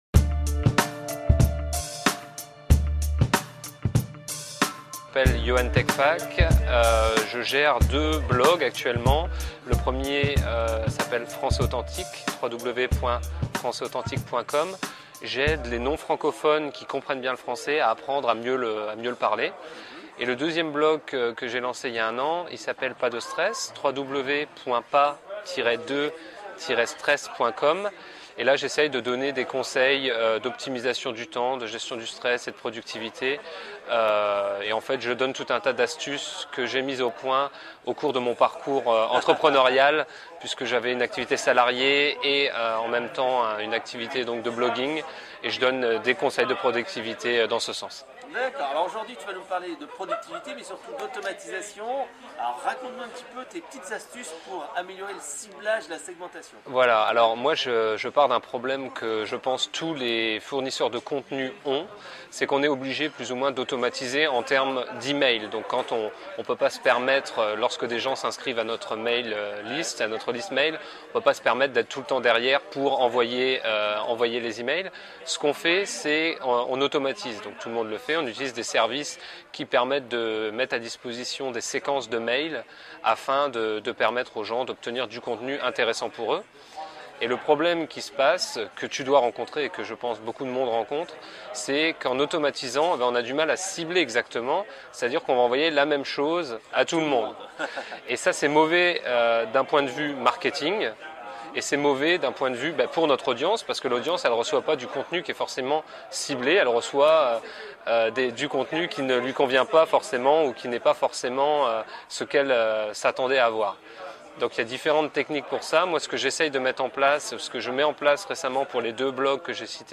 Comment faire de la segmentation Marketing - Interview